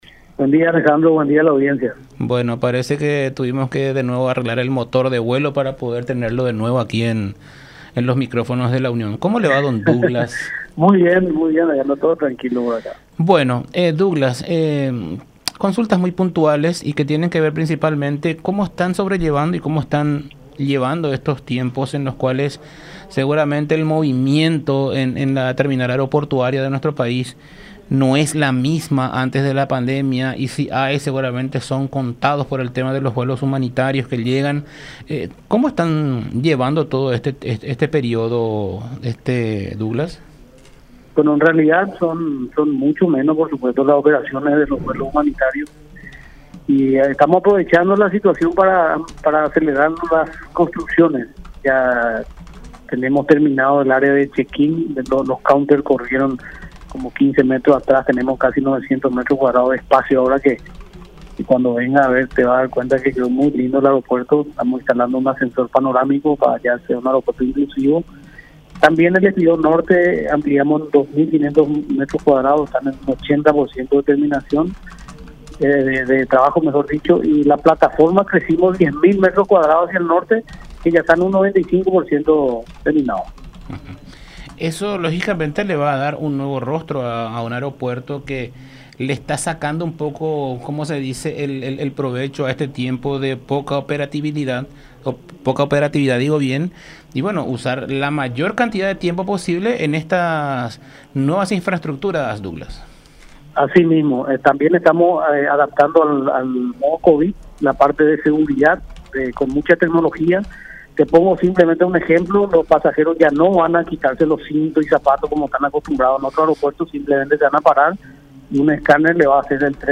“Va a tener que contarnos en todos los lugares donde va a estar, en qué hotel va a hospedarse y a dónde va a ir a hacer sus negocios. De ahí, de vuelta al aeropuerto para volver a Uruguay”, expuso Cubilla en diálogo con La Unión.